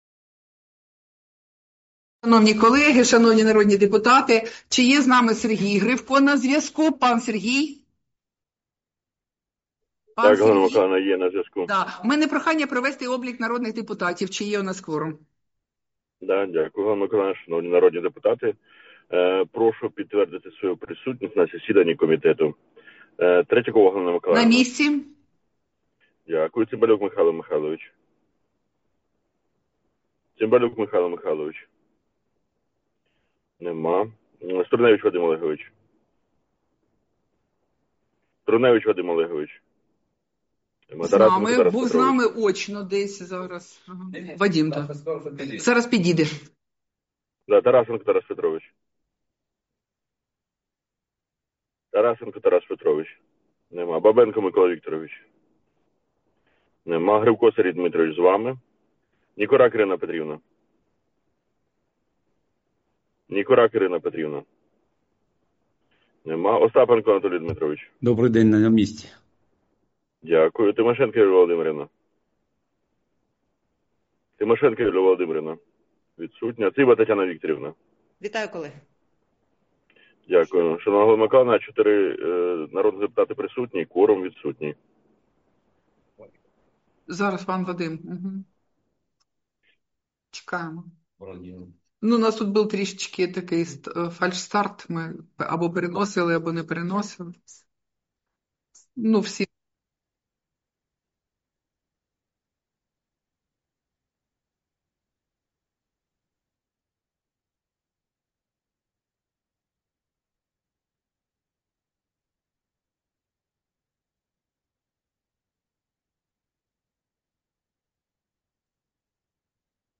Засідання Комітету від 1 квітня 2026 року